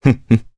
Crow-Vox-Laugh_jp.wav